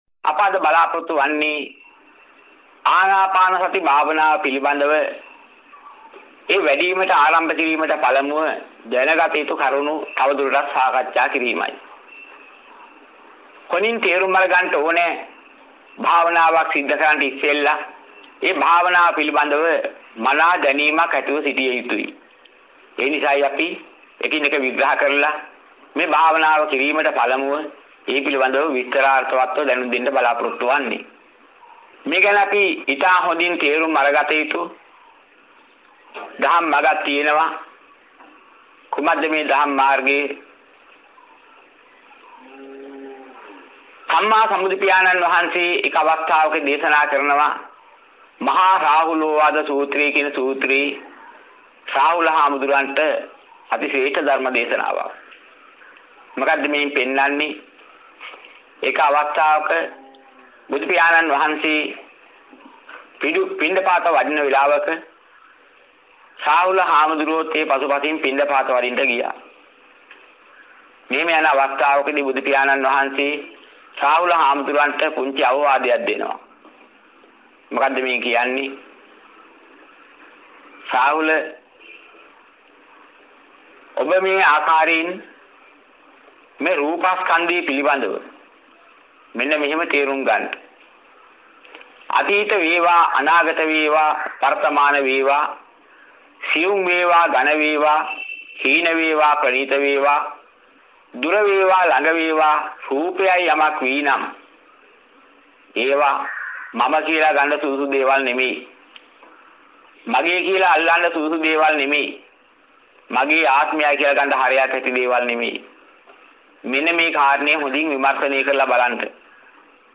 වෙනත් බ්‍රව්සරයක් භාවිතා කරන්නැයි යෝජනා කර සිටිමු 22:29 10 fast_rewind 10 fast_forward share බෙදාගන්න මෙම දේශනය පසුව සවන් දීමට අවැසි නම් මෙතැනින් බාගත කරන්න  (9 MB)